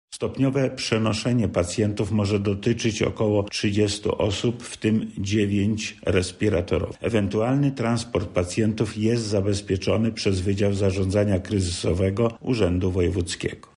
Braki zostaną uzupełnione jeszcze dziś – zapewnia wojewoda lubelski Lech Sprawka: